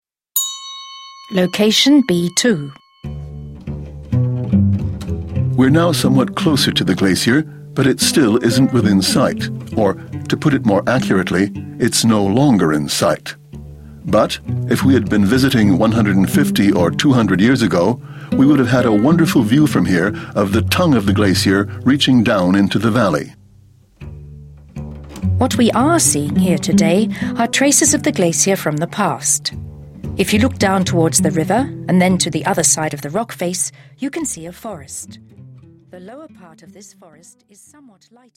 A clip on a trail overlooking Grindelwald.